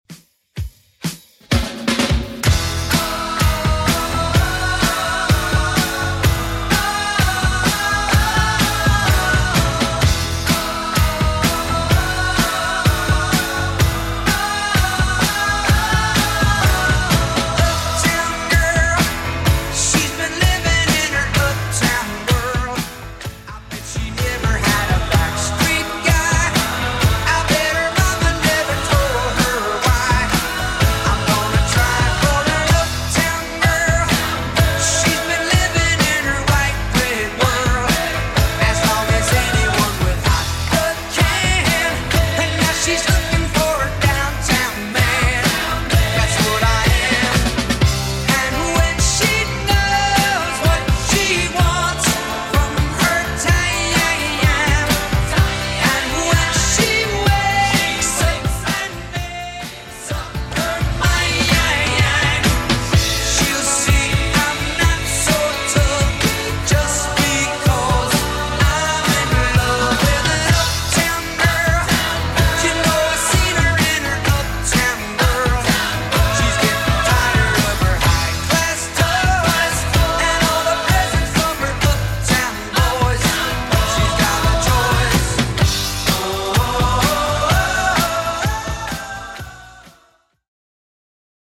Genre: 80's
BPM: 133